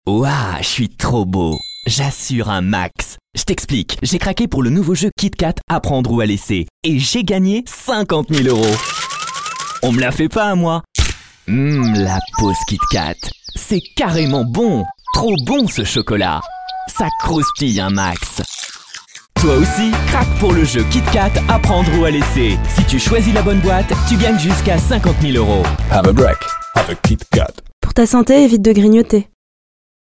VOIX OFF JEUNE
Un timbre plus léger, une énergie rajeunie, un phrasé plus direct — tout est ajusté avec précision selon les besoins du projet.
Une voix jeune, oui.
2. NESTLE adolescent malicieux, facetieux